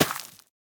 Minecraft Version Minecraft Version snapshot Latest Release | Latest Snapshot snapshot / assets / minecraft / sounds / item / plant / crop2.ogg Compare With Compare With Latest Release | Latest Snapshot